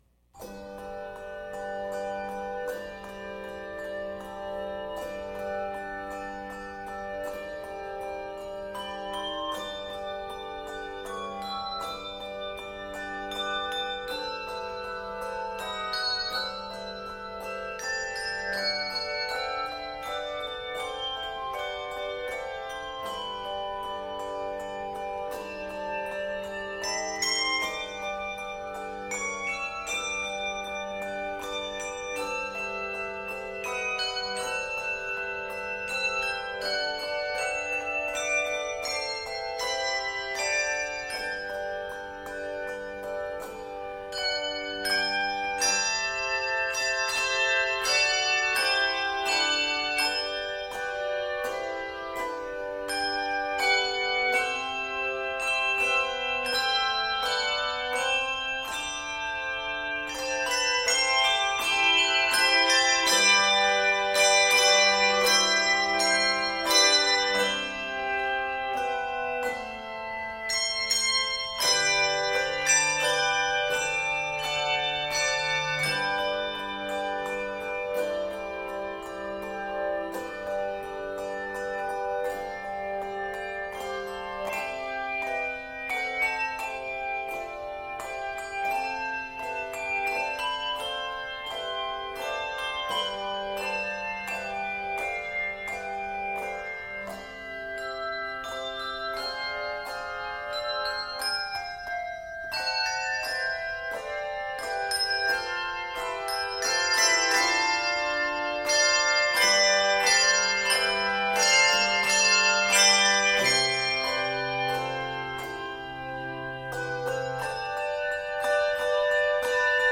Simultaneously traditional and modern
Keys of G Major and C Major.
Octaves: 3-5